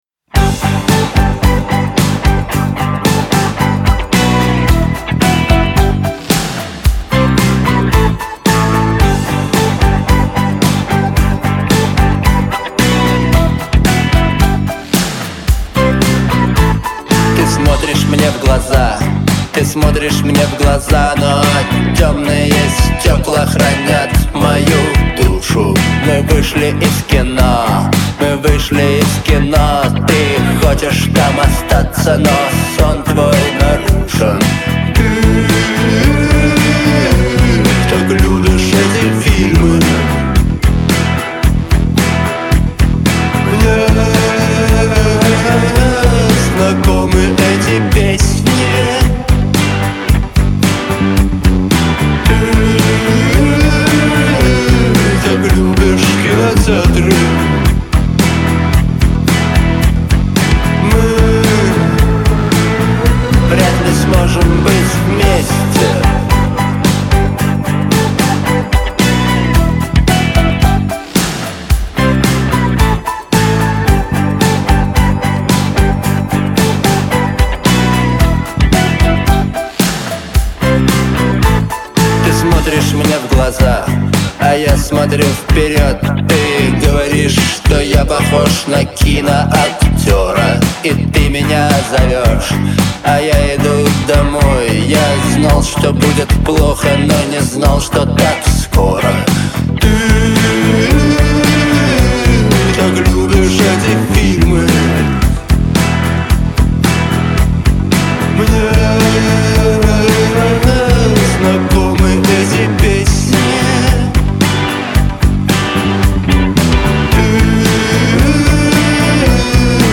Трек размещён в разделе Русские песни / Альтернатива.